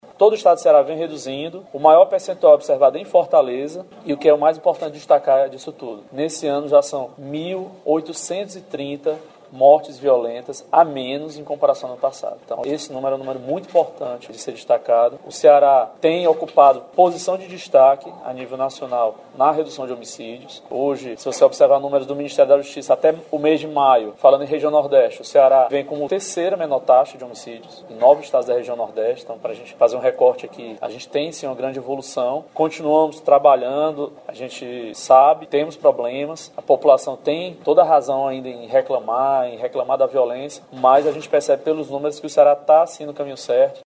O secretário André Costa falou sobre a redução dos crimes, que está sendo uma prática constante.